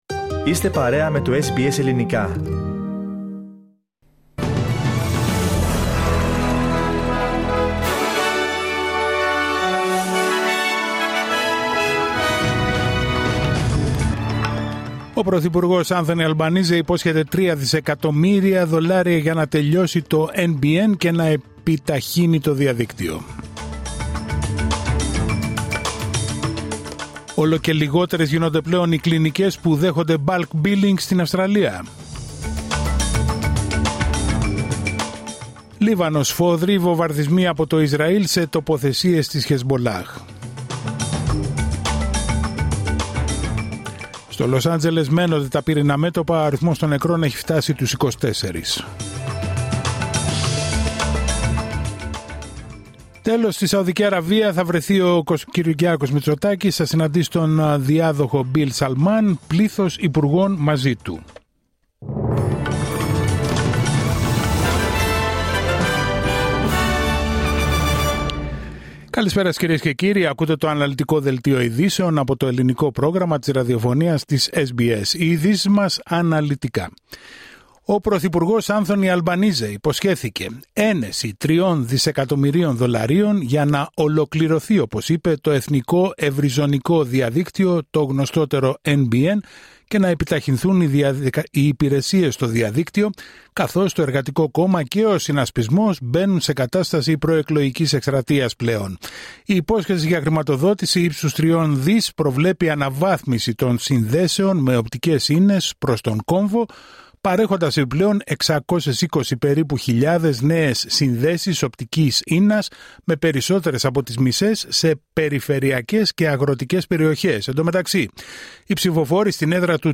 Δελτίο ειδήσεων Δευτέρα 13 Ιανουαρίου 2025